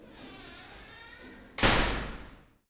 METAL SQUEEK.WAV